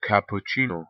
گوش دهیدi/ˌkæpʊˈn/, ایتالیایی: [kapputˈtʃiːno]; ج.: cappuccini; از آلمانی Kapuziner[۶]) از لاتین Caputium می‌آید که از کلمه کاپوش [۷] به معنی و ترگمان "کله پوش" گرفته شده است.
Cappuccino_Pronunciation.ogg